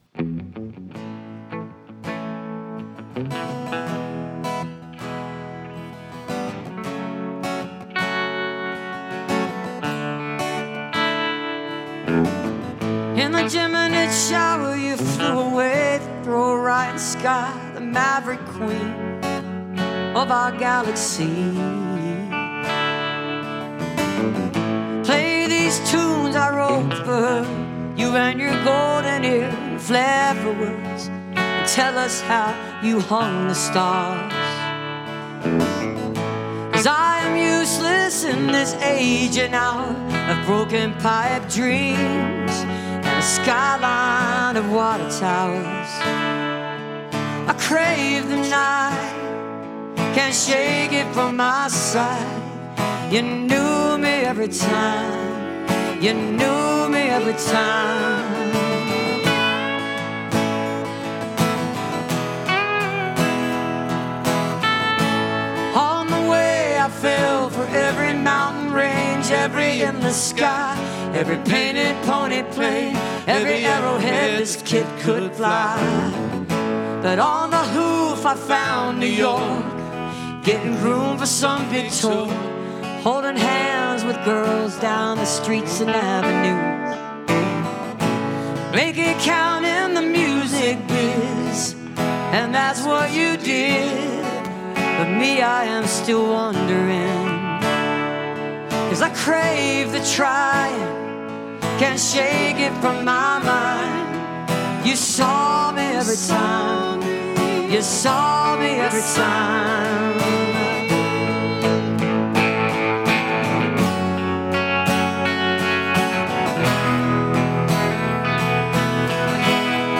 (audio capture from webcast)